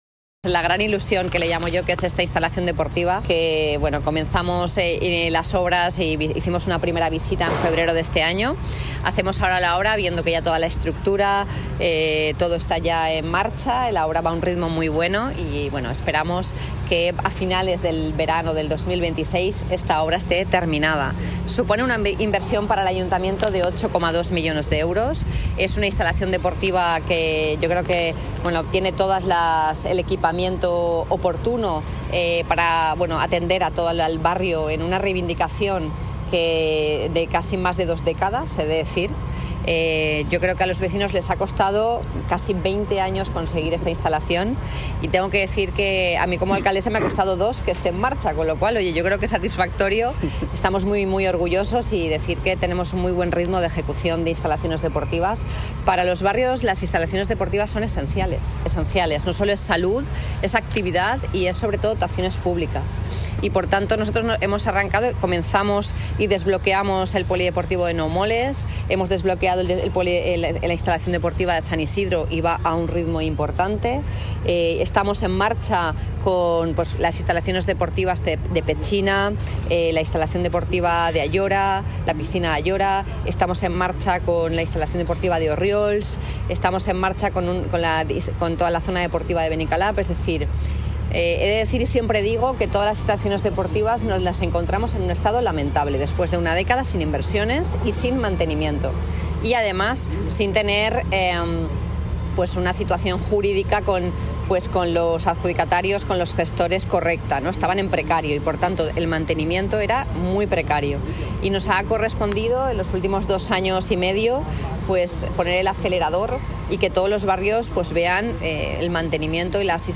Así se ha manifestado este jueves la alcaldesa de València, María José Catalá, en la visita a las obras del polideportivo municipal del barrio de Sant Isidre, que han supuesto una inversión del Ayuntamiento de València de más de ocho millones de euros.